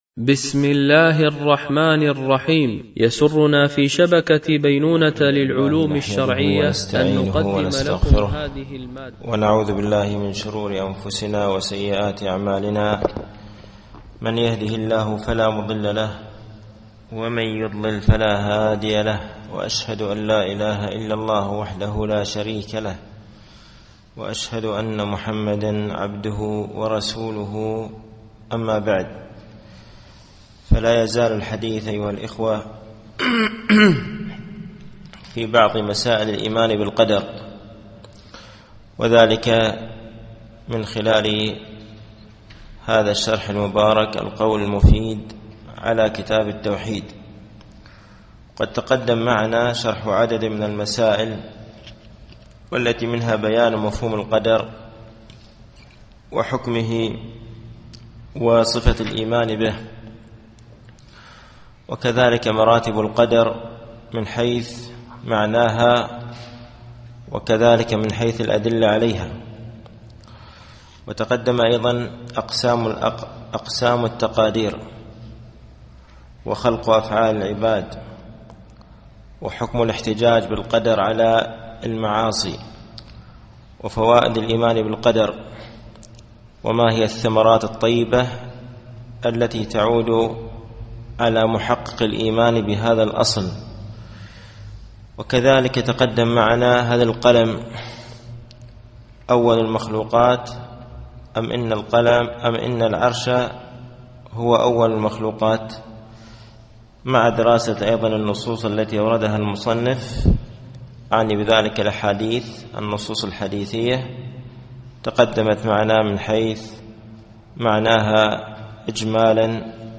التعليق على القول المفيد على كتاب التوحيد ـ الدرس التاسع و الخمسون بعد المئة